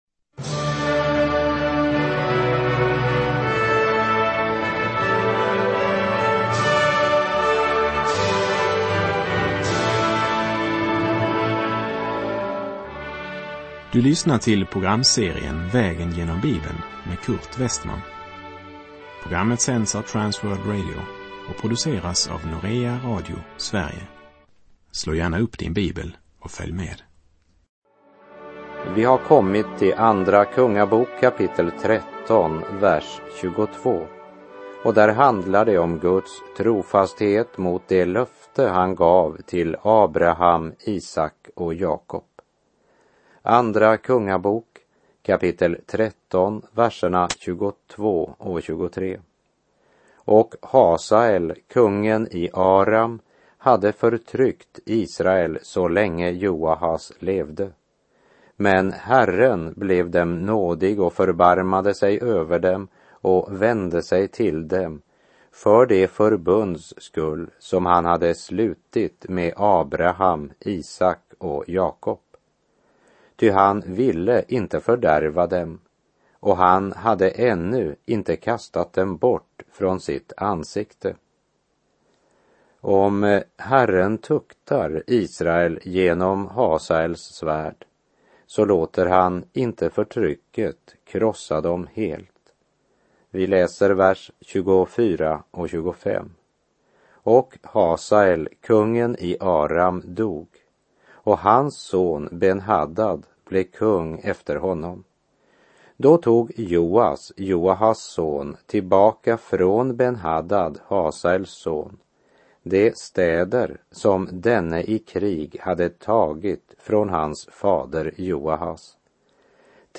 Syftet är att vara ett 30-minuters radioprogram som tar lyssnaren systematiskt genom hela Bibeln.